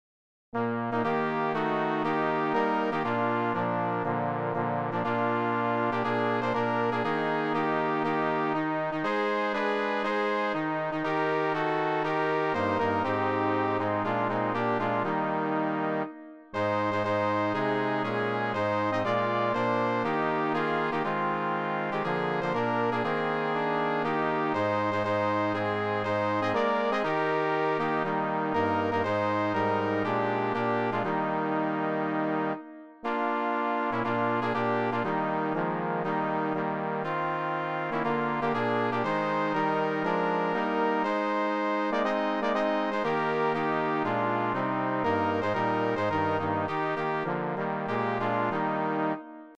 Cor mixt
SA-INAINTAM-midi.mp3